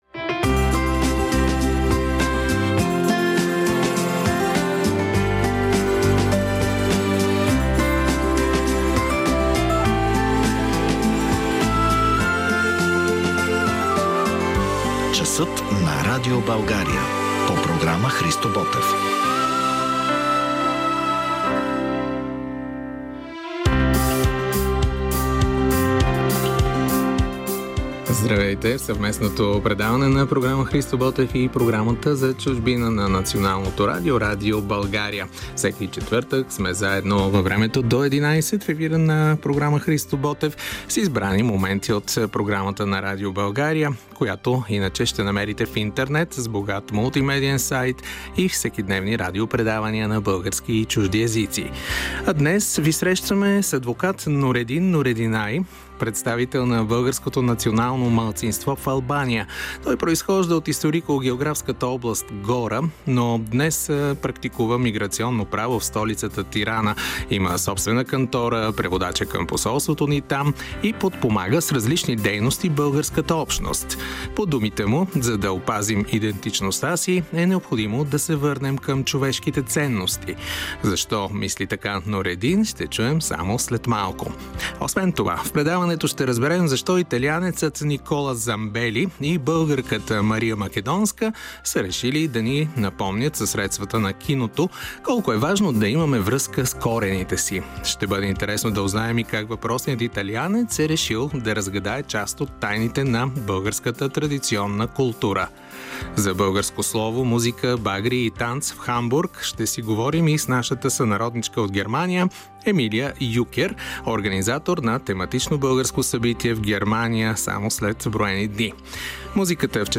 В брой 28 от Часът на Радио България, излъчен на 28 ноември 2024: